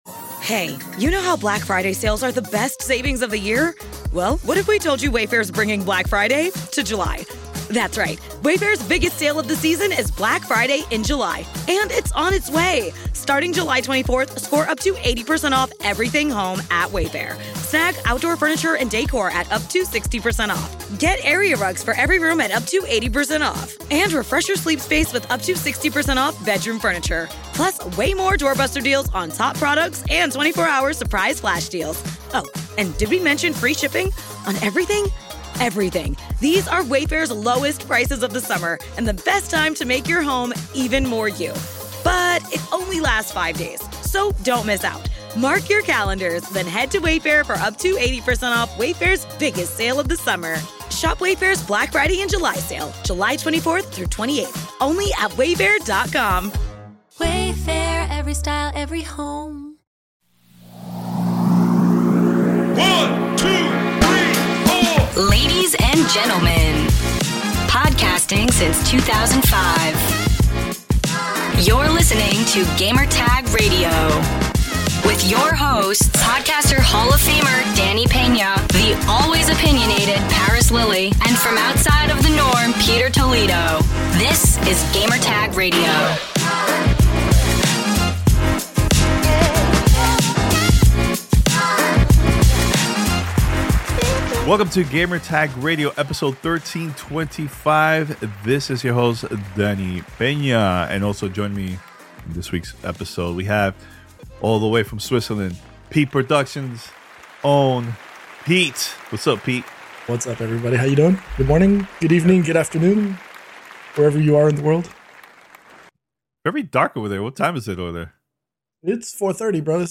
Suicide Squad, State of Play Reaction and Halo The Series Season 2 Interview